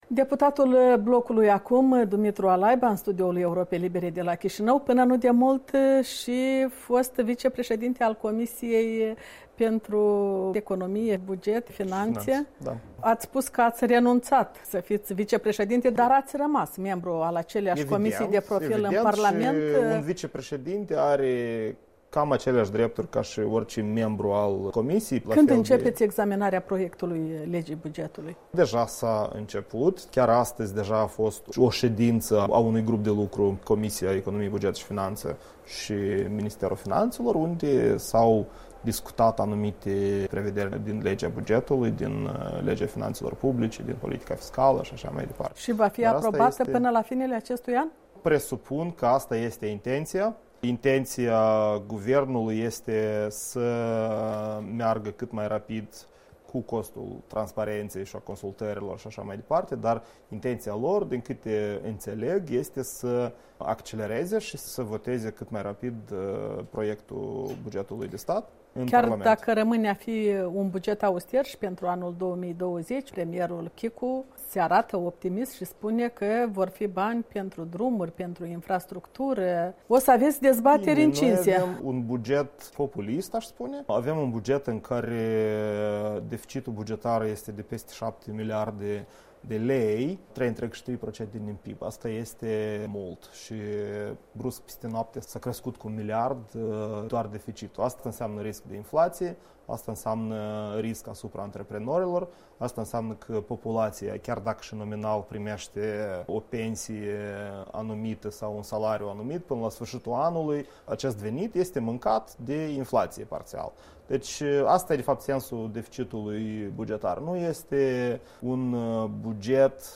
Interviu cu Dumitru Alaiba